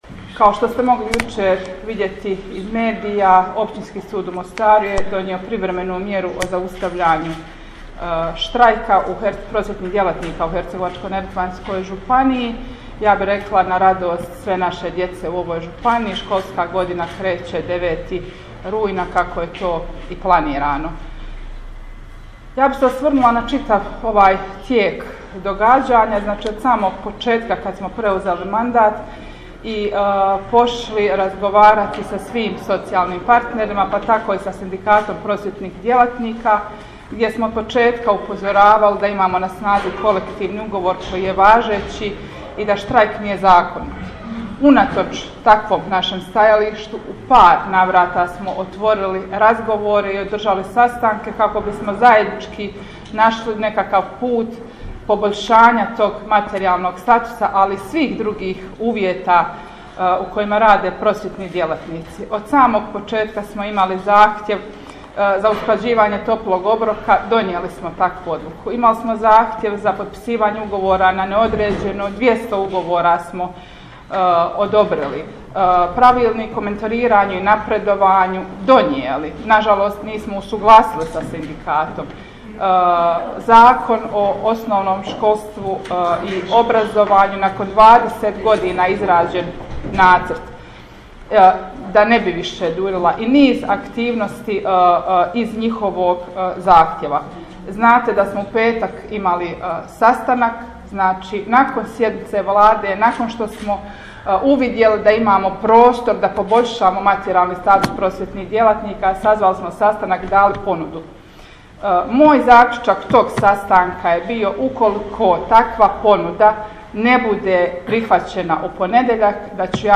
Audio zapisi izjava predsjednice Buhač i ministra Velagića dostupni su u privitku:
Audio: Predsjednica Marija Buhač